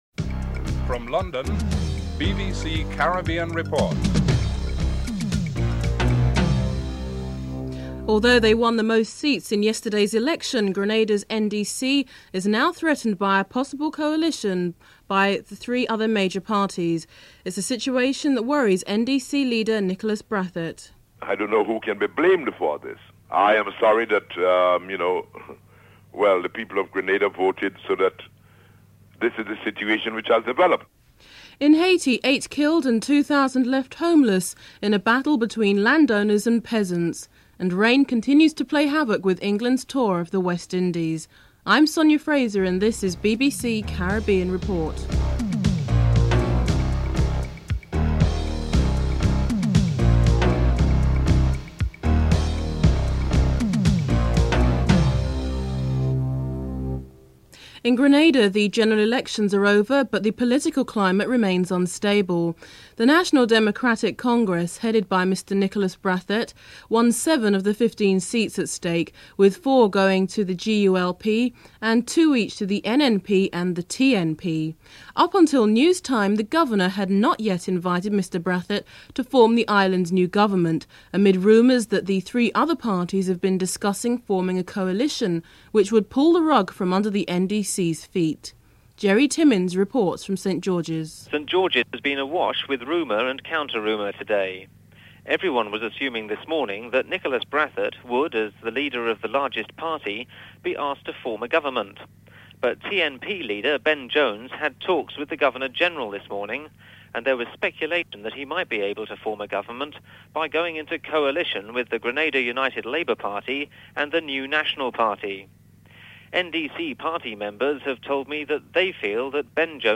Interview with Nicholas Brathwaite, leader of the NDC (00:57-08:52)
Christopher Martin-Jenkins reports from Georgetown, Guyana (12:56-15:04)